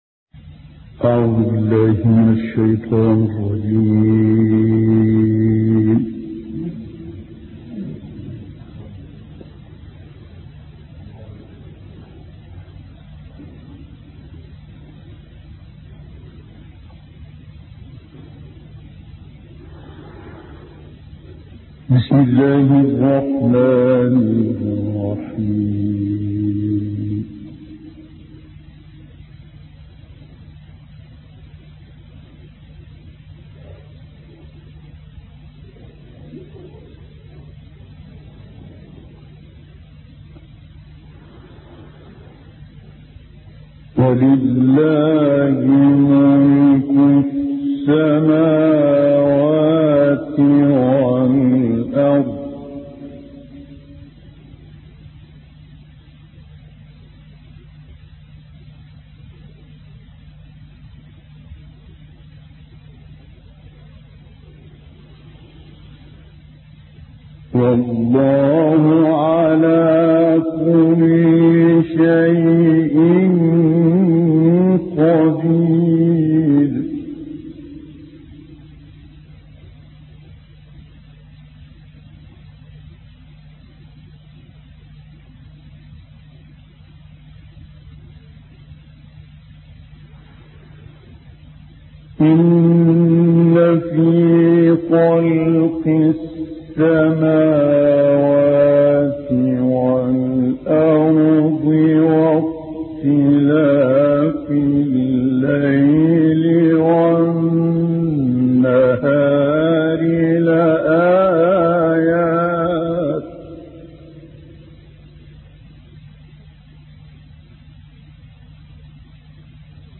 تلاوت آیاتی از سوره آل عمران توسط استاد شیخ متولی عبدالعال